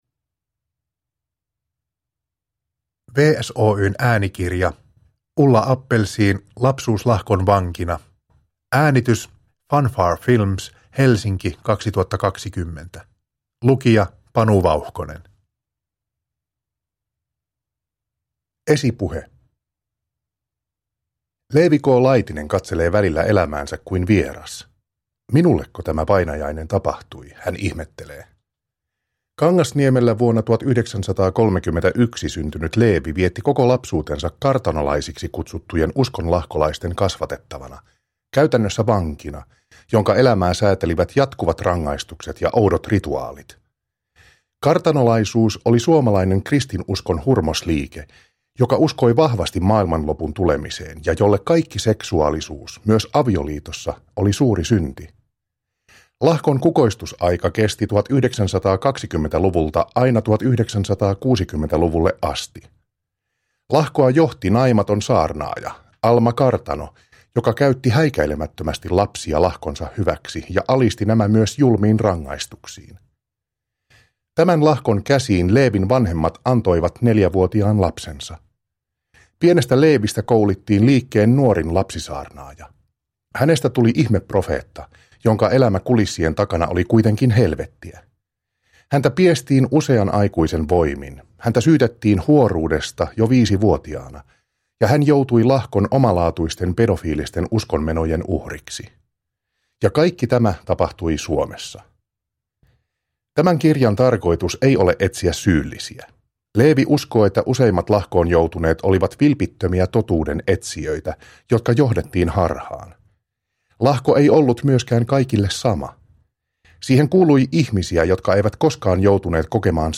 Lapsuus lahkon vankina – Ljudbok – Laddas ner